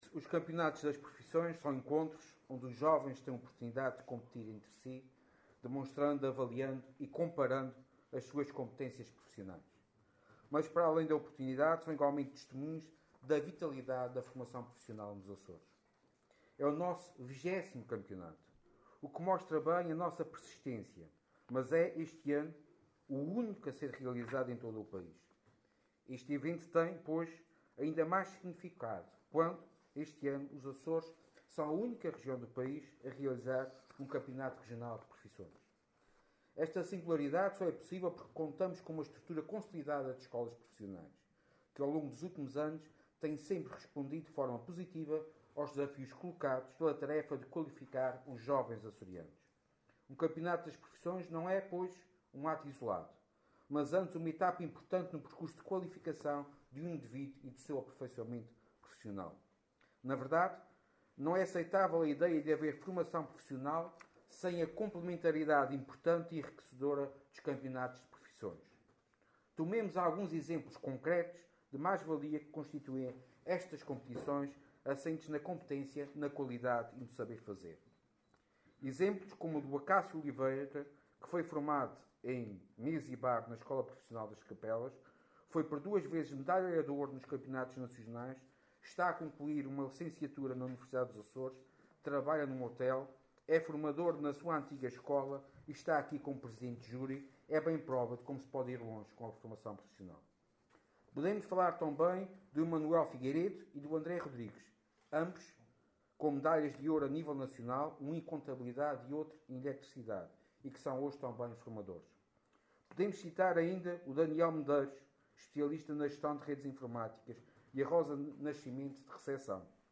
Intervenção do Vice-Presidente do Governo Regional